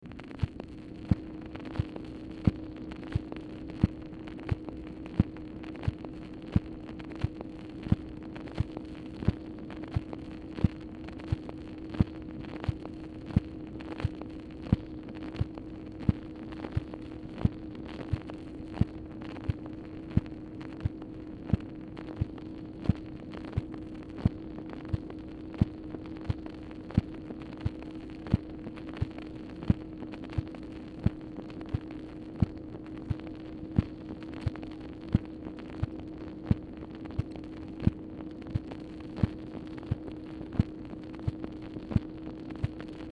Telephone conversation # 7517, sound recording, MACHINE NOISE, 5/2/1965, time unknown | Discover LBJ
Telephone conversation
Format Dictation belt
Location Of Speaker 1 Mansion, White House, Washington, DC